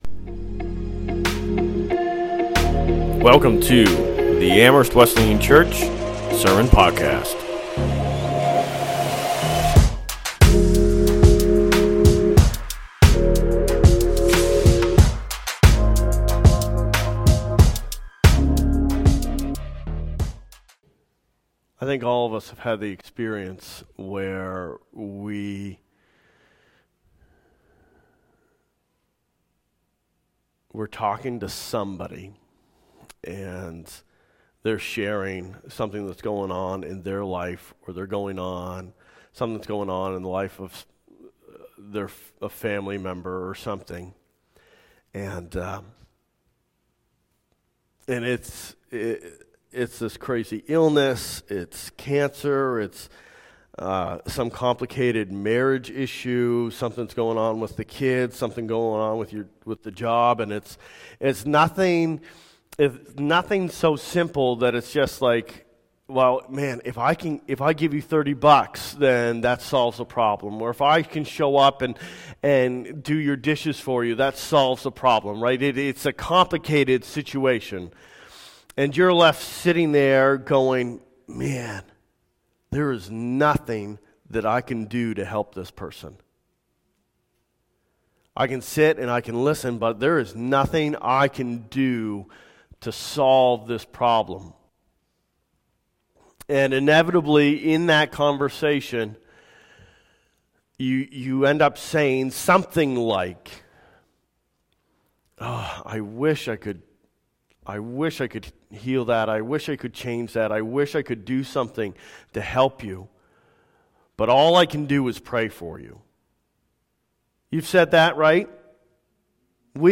Sermons | Amherst Wesleyan Church